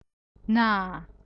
{naa.} <)) / {naar}